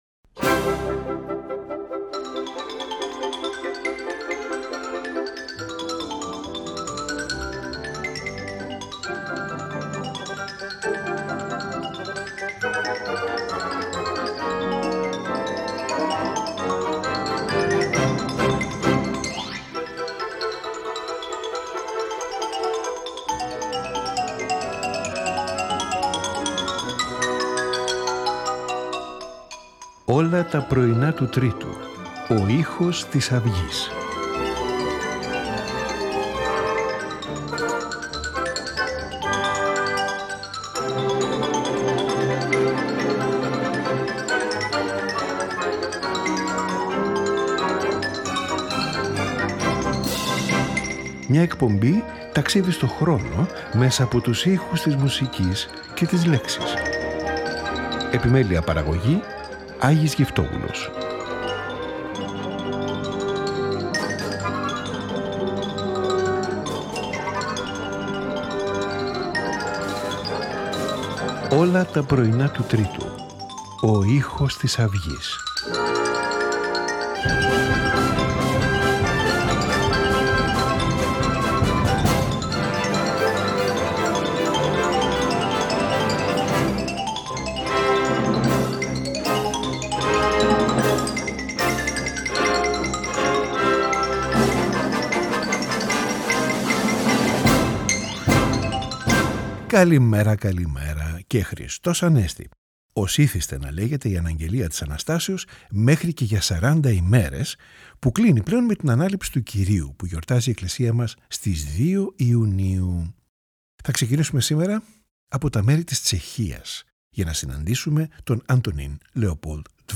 Serenade for strings in E
for violin and orchestra
for oboe, bassoon, winds, strings and baso continuo